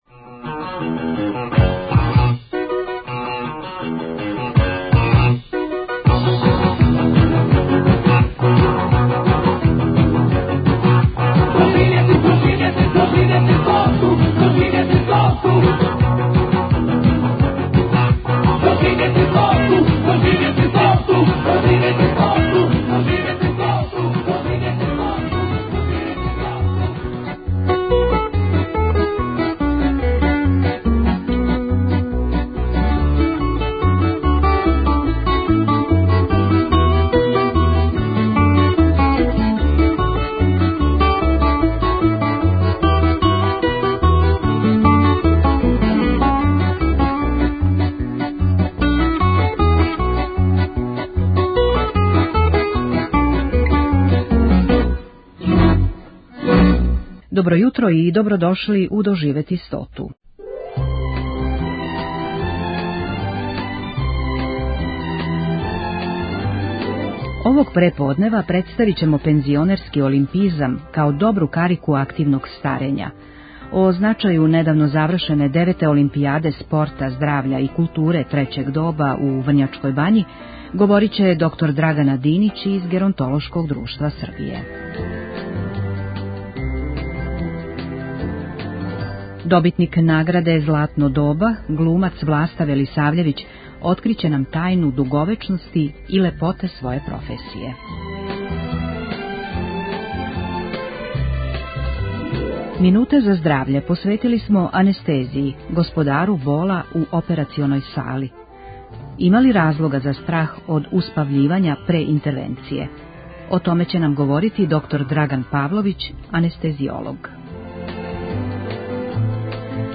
Представићемо лаурета XIV Фестивала "Златно доба" глумца Власту Велисављевића. У ексклузивном интервјуу нашем програму откриће нам тајну дуговечности и лепоте своје професије.